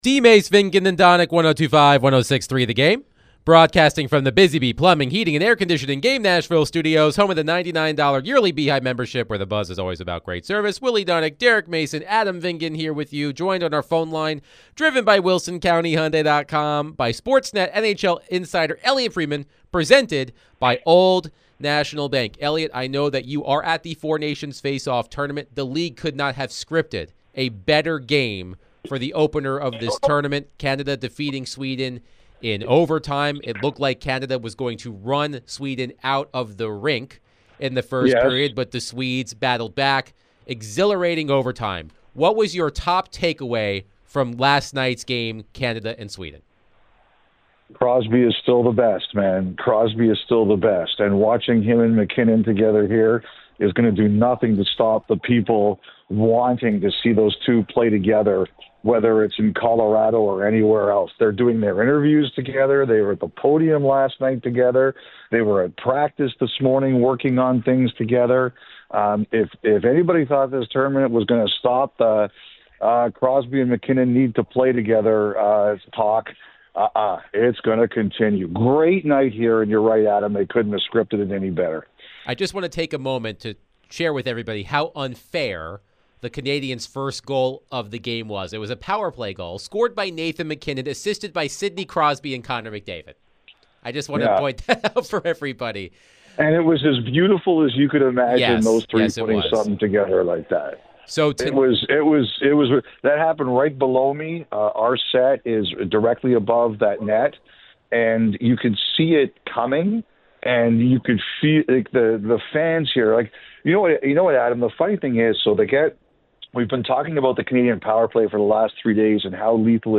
NHL Insider Elliotte Friedman joined DVD to discuss the 4 Nation Faceoff, Preds and more